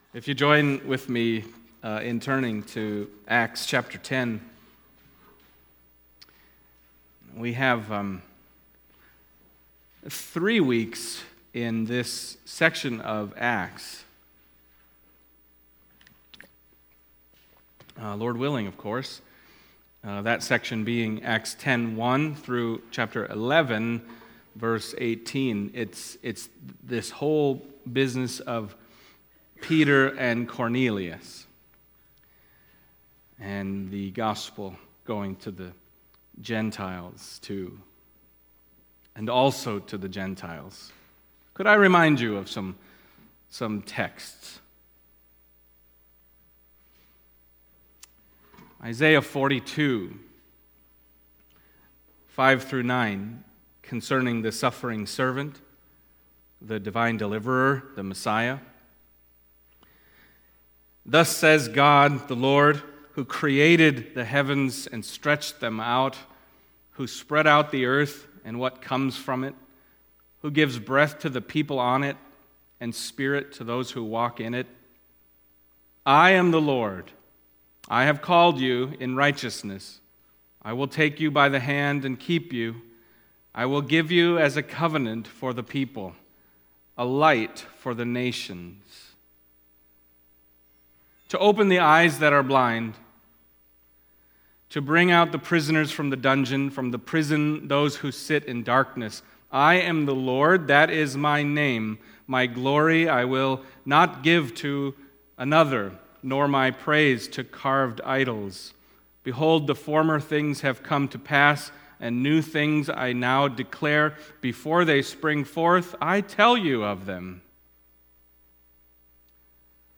Acts Passage: Acts 10:1-23 Service Type: Sunday Morning Acts 10:1-23(a